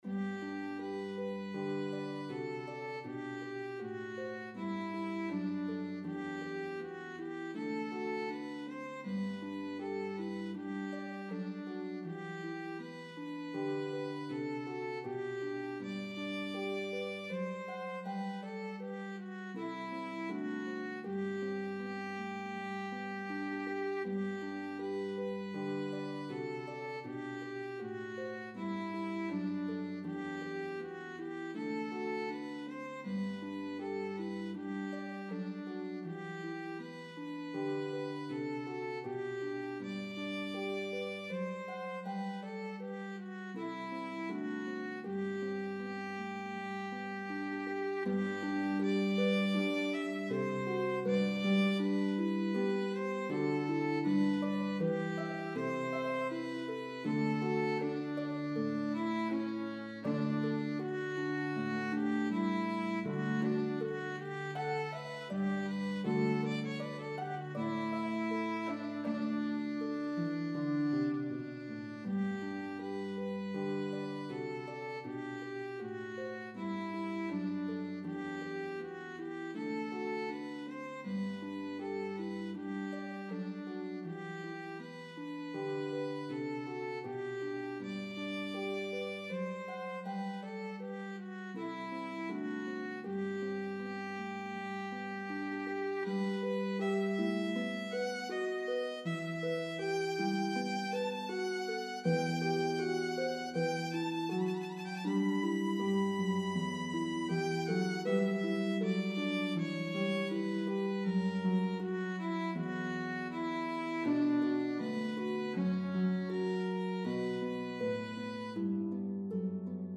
This well known Baroque piece